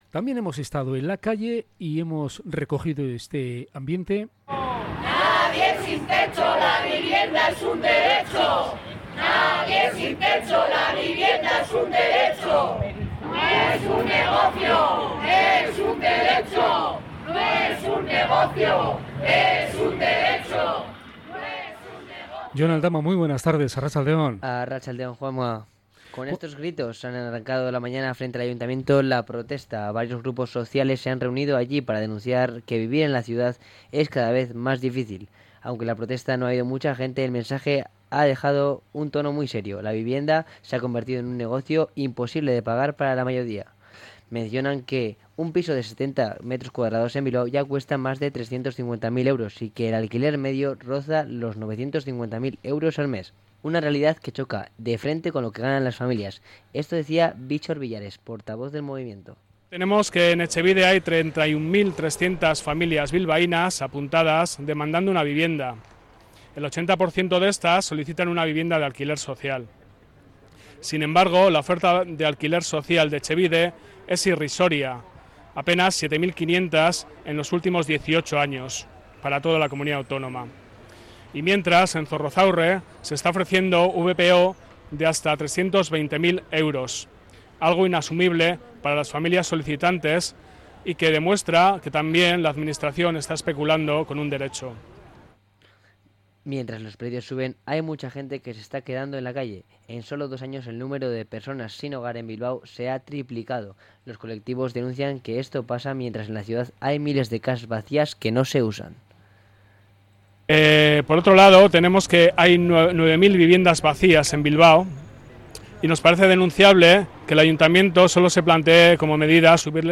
Protestas frente al ayuntamiento sobre el precio de la vivienda / Radio Popular - Herri Irratia
cronica_vivienda.mp3